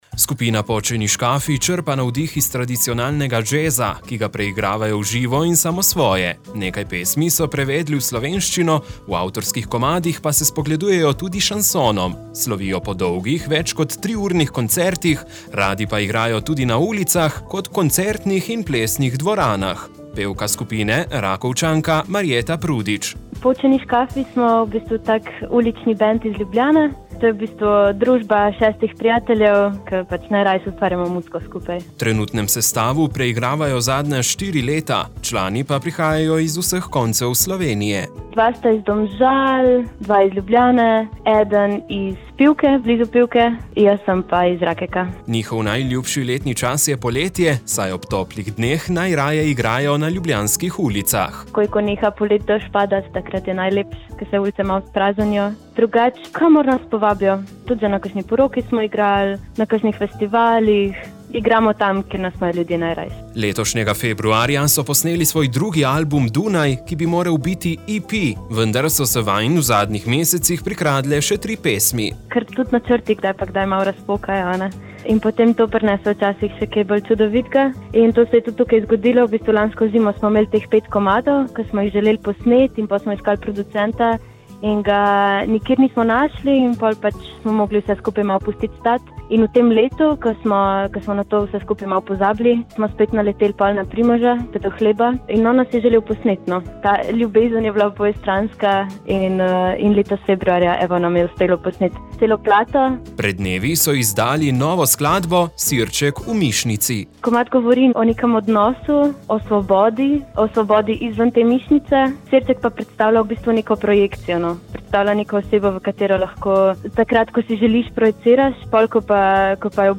ulični swing bend